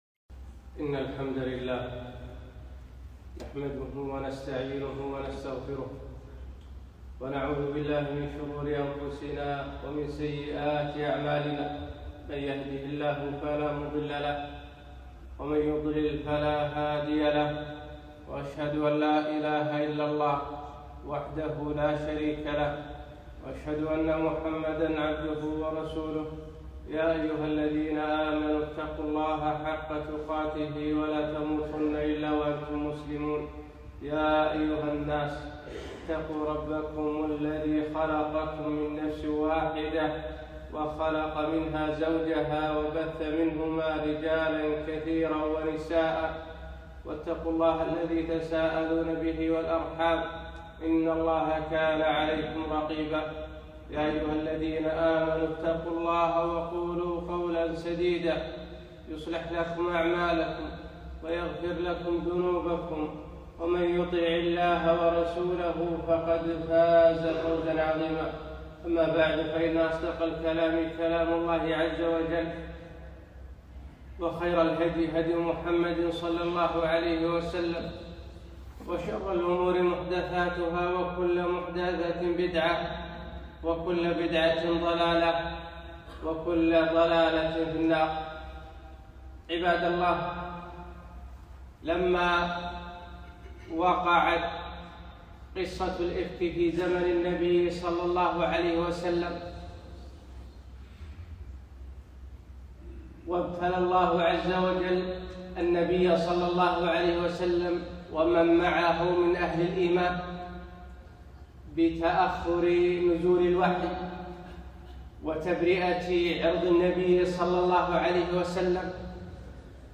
خطبة - أحداث إيران وقصة النبي صلى الله عليه وسلم